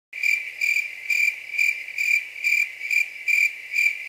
Cricket Sound